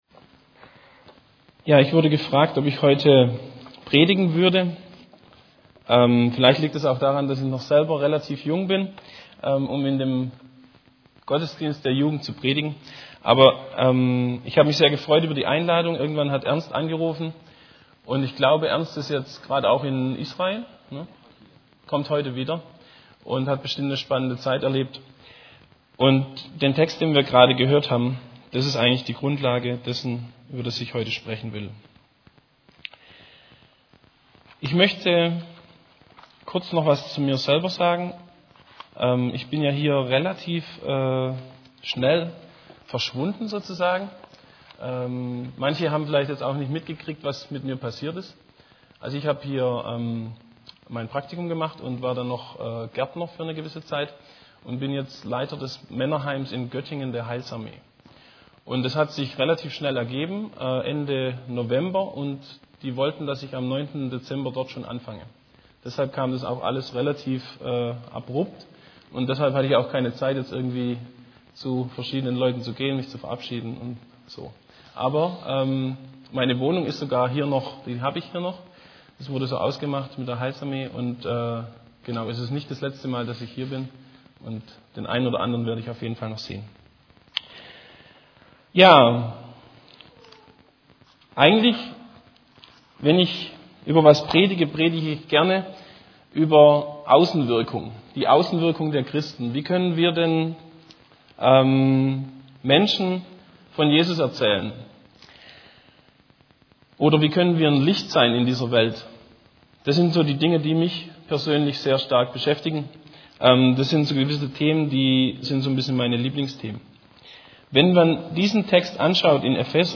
> Übersicht Predigten Wie Leben in der Gemeinde funktionieren kann Predigt vom 28.